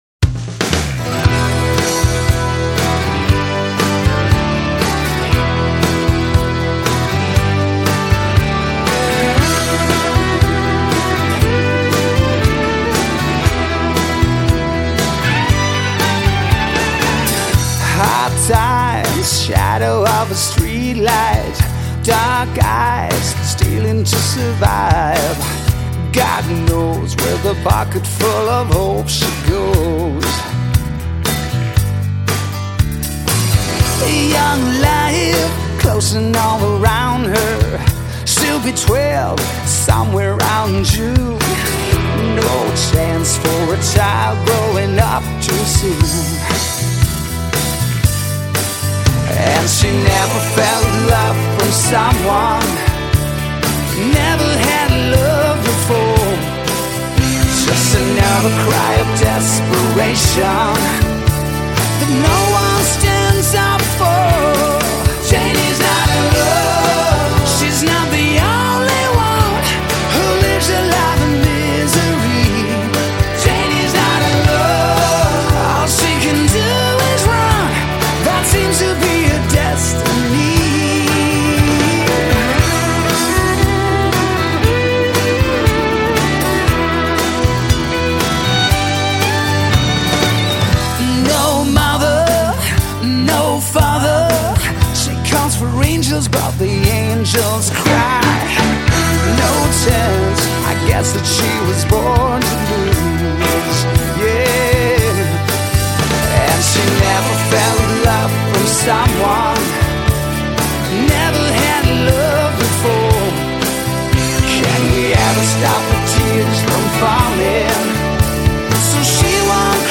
Жанр: Melodic Hard Rock